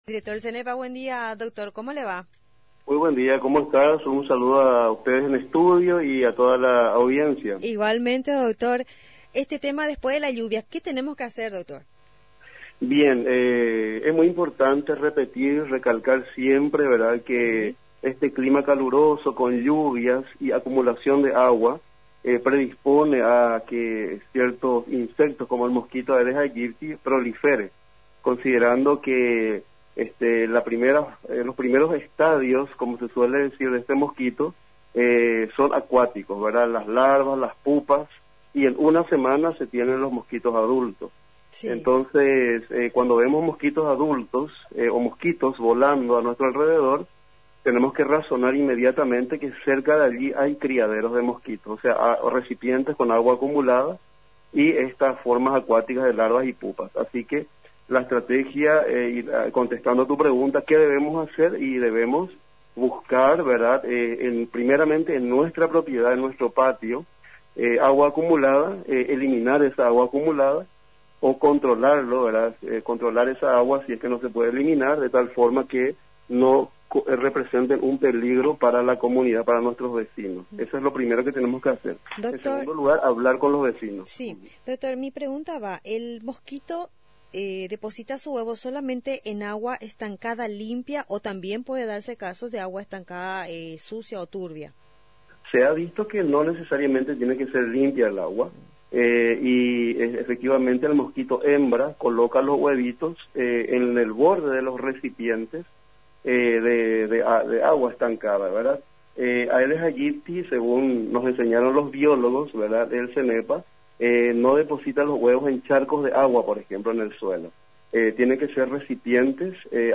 El Director de SENEPA, Dr. Hernán Rodríguez, en comunicación con nuestra emisora recalcó que el clima caluroso con lluvia y acumulación de agua predispone la proliferación de diferentes tipos de insectos, en especial el mosquito Aedes aegypti, considerando que necesariamente requiere de acumulación de agua, las larvas primero, las pupas y en una semana ya se tiene los mosquitos adultos.